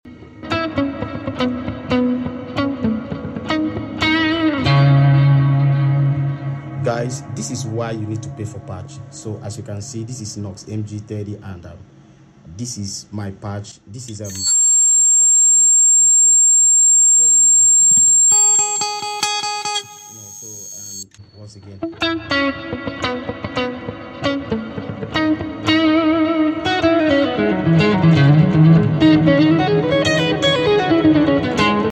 My Patch Vs Factory Presets Sound Effects Free Download